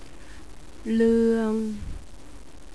one pronounce for    " ua " two pronounces             " ua "   " uer "